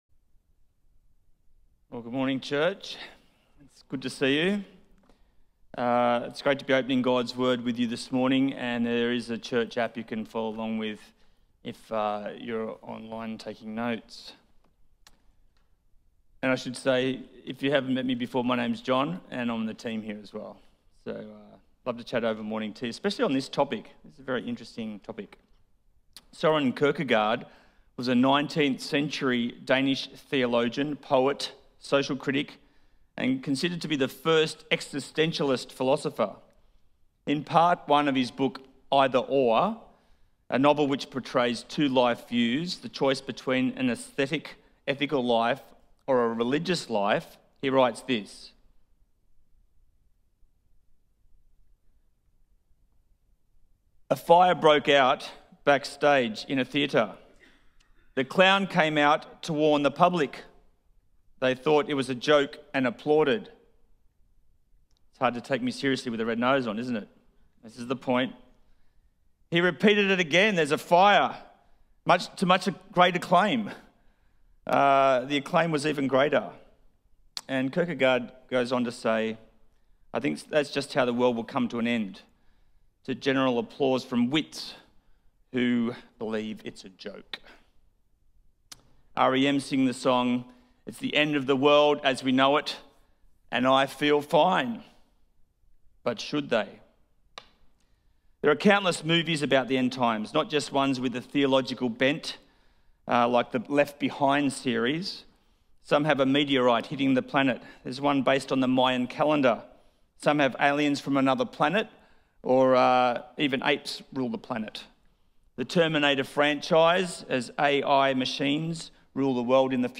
End, Hope, and How We Live: A Sermon